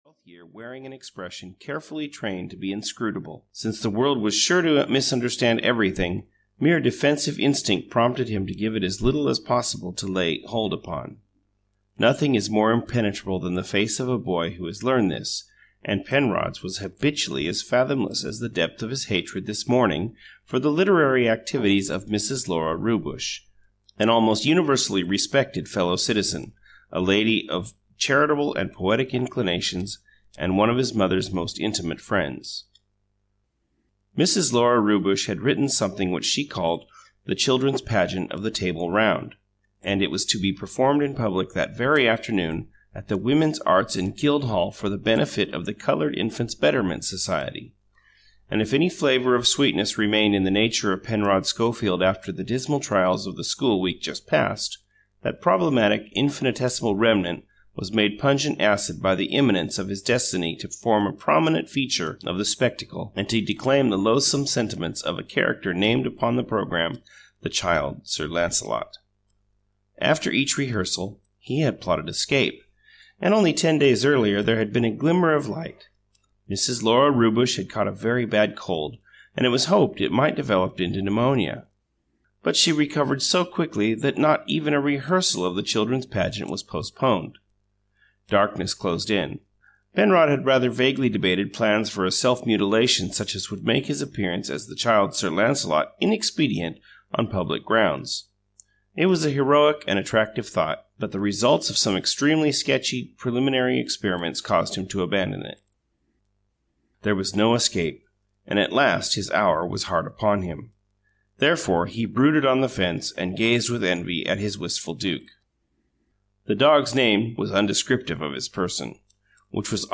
Penrod (EN) audiokniha
Ukázka z knihy